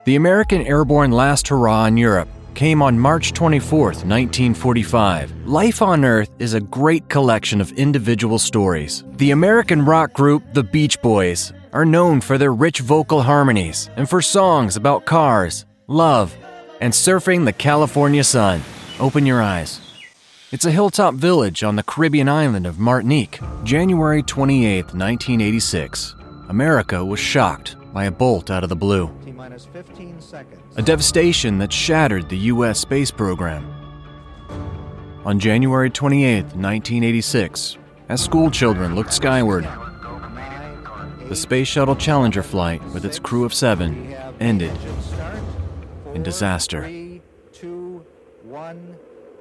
Friendly, Warm, Conversational.
Documentary